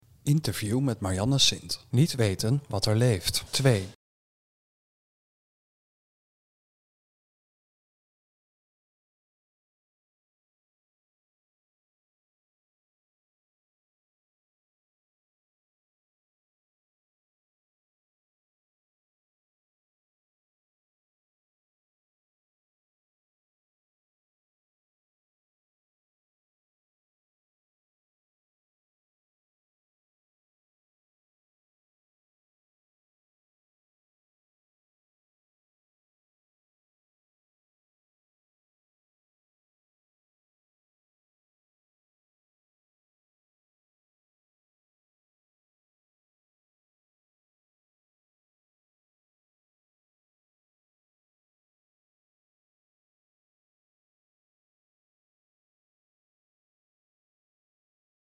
Interview met Marjanne Sint